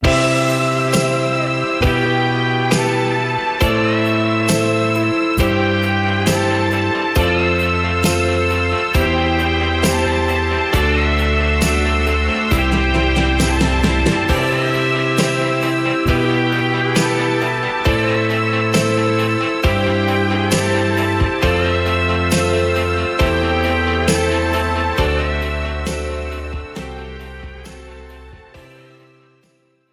This is an instrumental backing track cover.
• Key – C
• Without Backing Vocals
• With Fade